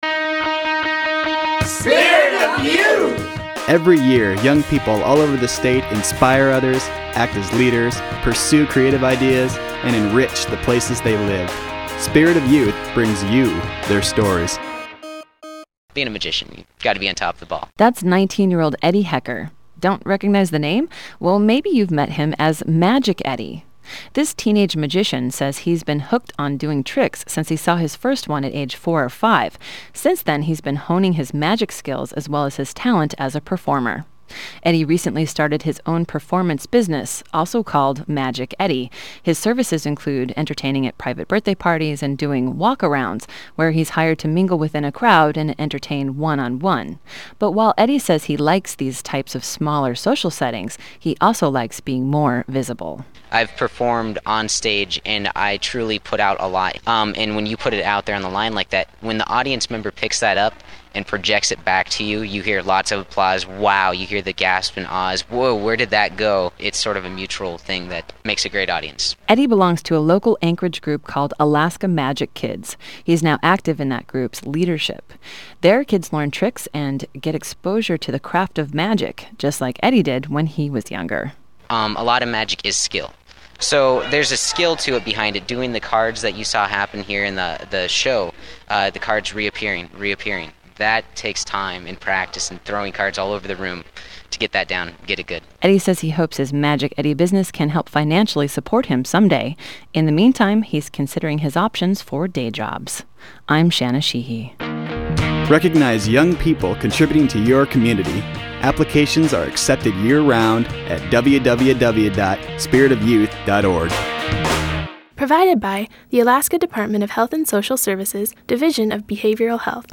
I had the opportunity to be interviewed by the Alaska Teen Media Institute in recognition of the Spirit of Youth Award. It is an award given to youth who are involved in their community.